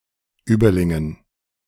Überlingen (German pronunciation: [ˈyːbɐˌlɪŋən]
De-Überlingen.ogg.mp3